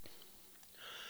Type: Barbershop
Each recording below is single part only.